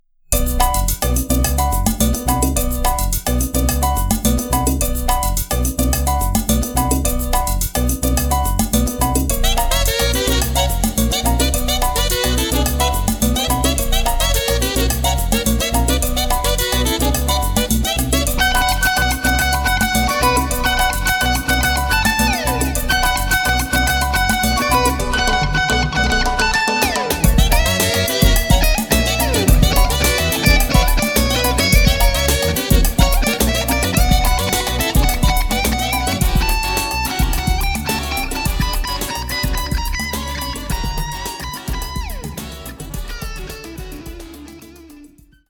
instrumental track